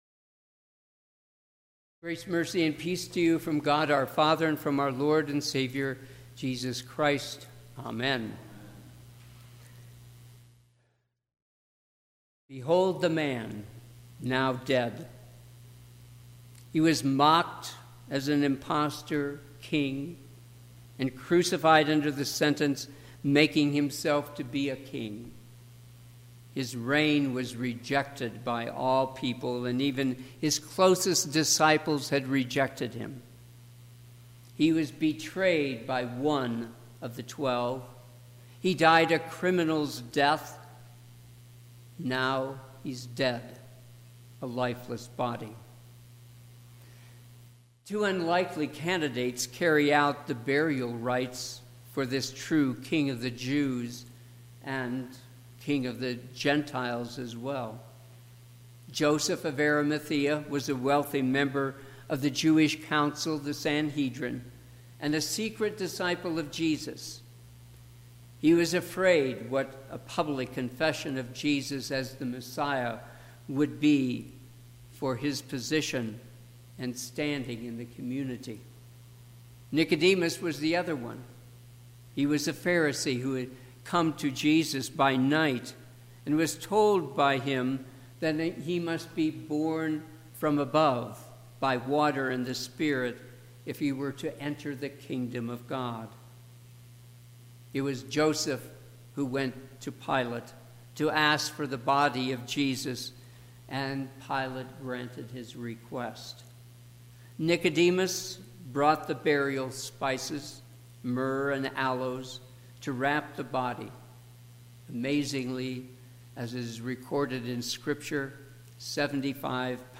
Easter Vigil, Service of Light
Sermon – 4/20/2019